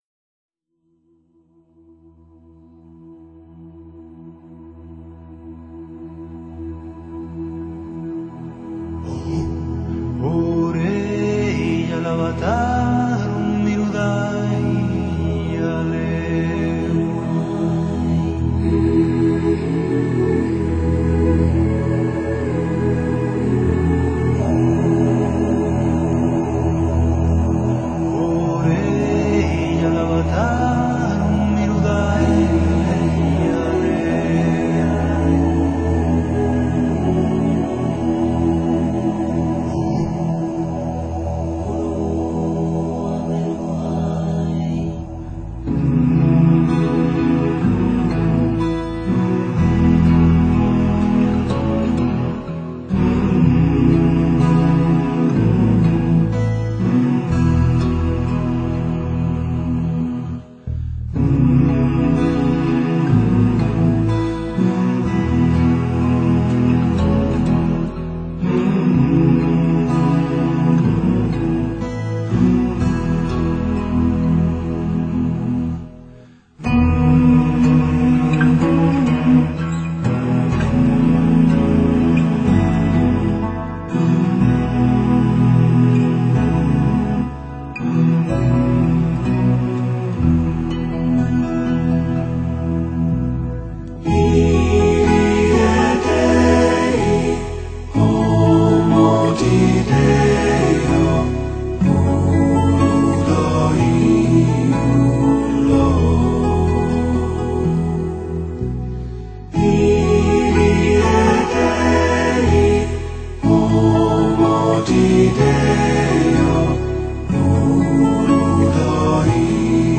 专辑类型：民族乐器的完美结合
附注说明：冥想时聆赏
完美的男女合声，结合印加吉他、苏格兰风笛等高地民族乐器等，给您如临海拔数千公尺的人间仙境！